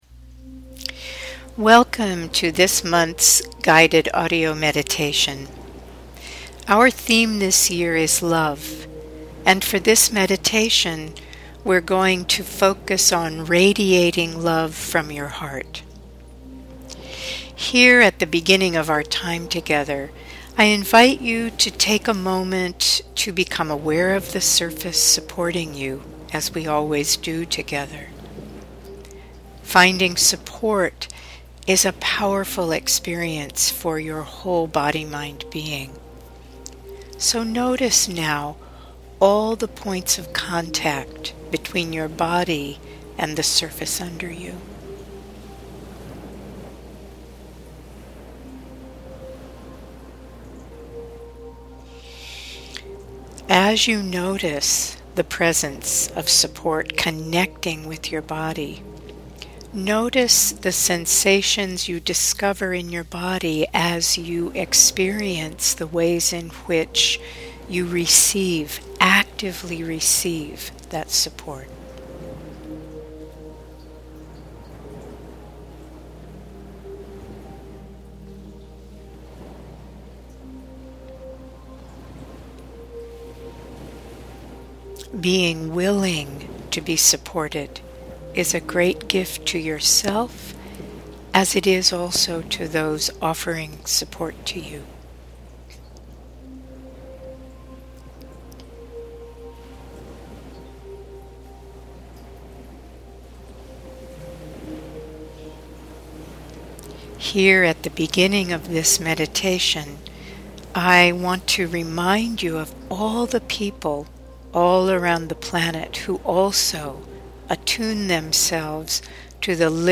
November 2017 Audio Meditation